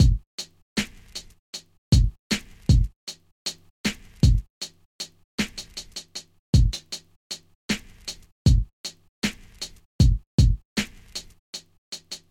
献给日本的爱 C至D大调 95
描述：实际上这是一个古筝/古筝。
Tag: 95 bpm Ethnic Loops Sitar Loops 3.40 MB wav Key : Unknown